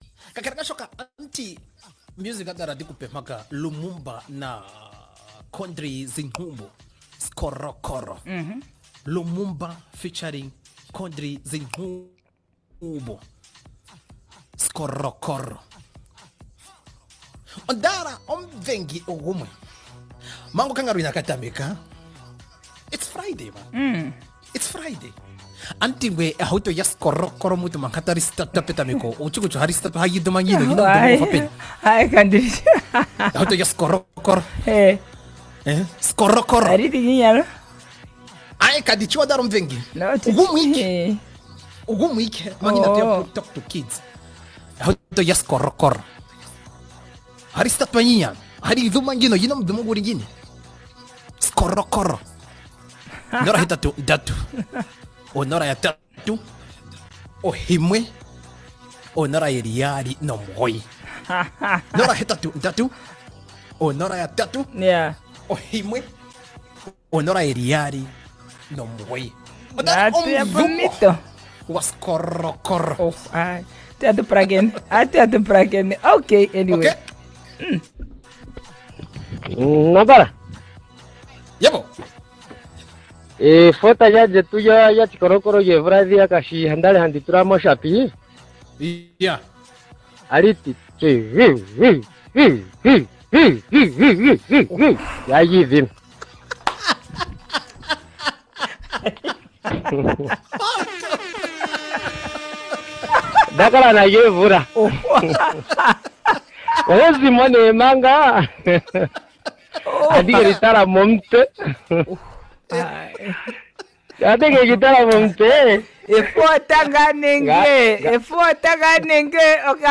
Voice of a Skorokoro Car